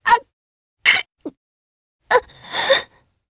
Selene-Vox_Dead_jp_b.wav